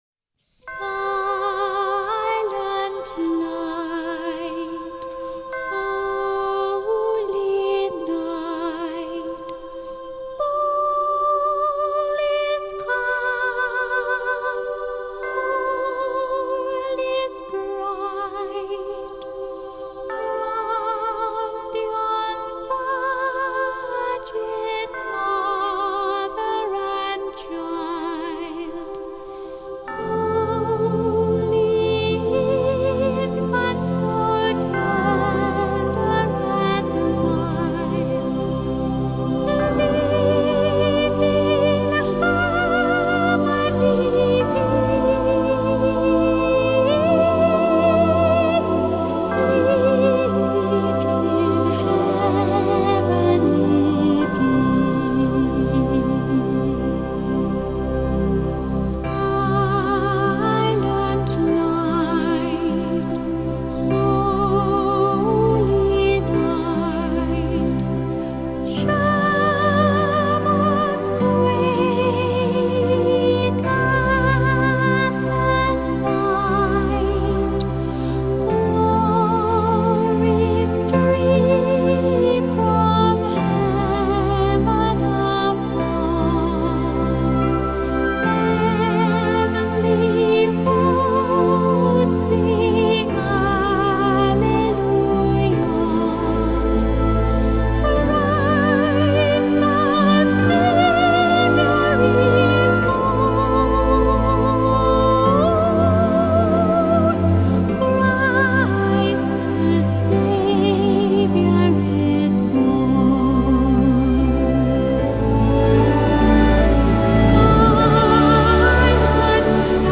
Genre: Crossover